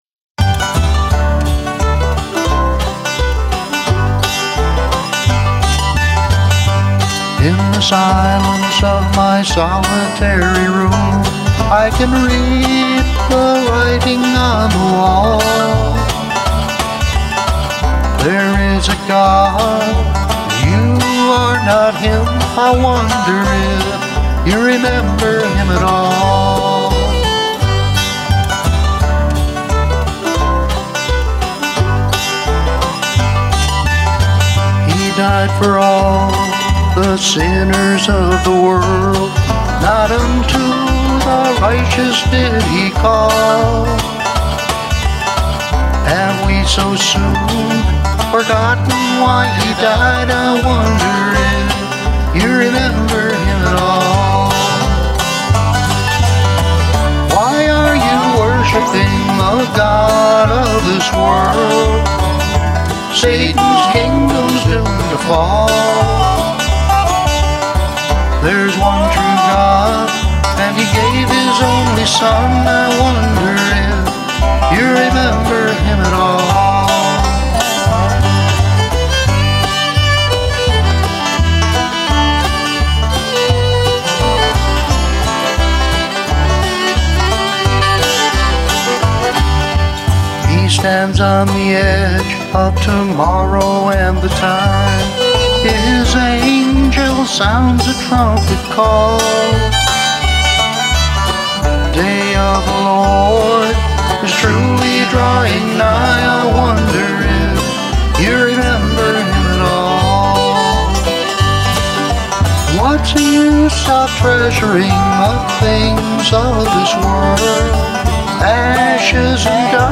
good prices & selection on country & bluegrass gospel music